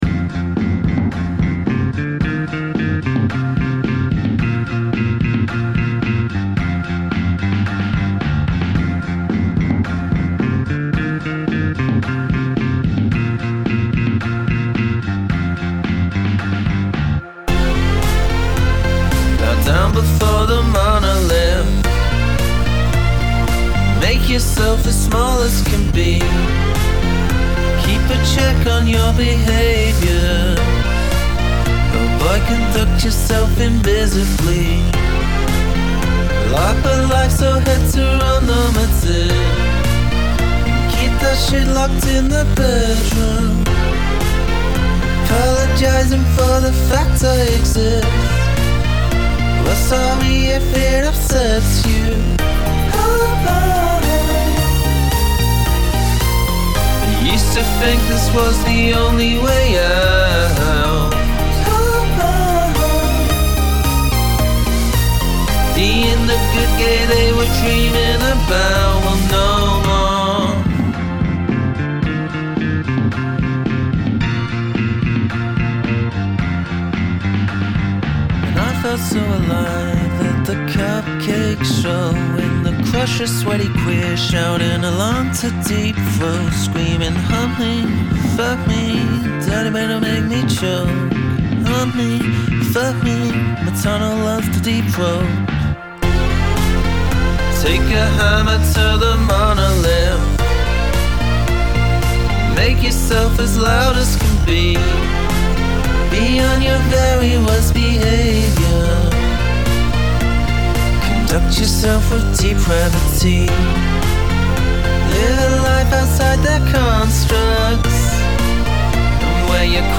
Downward Modulation
I like the feel of the bass synth.
that bassline is ridiculously catchy.